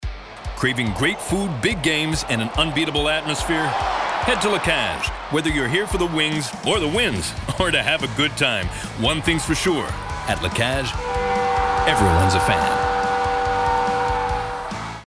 Commercial (La Cage) - EN